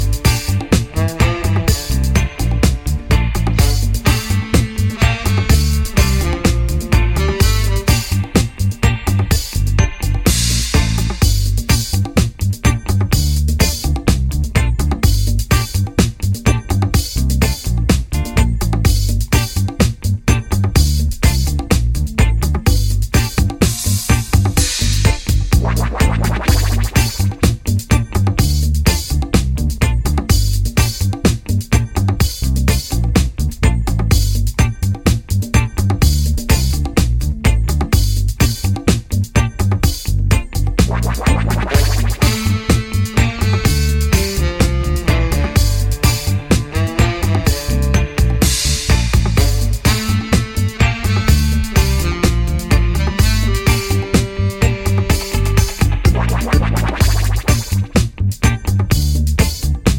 no Backing Vocals Reggae 4:00 Buy £1.50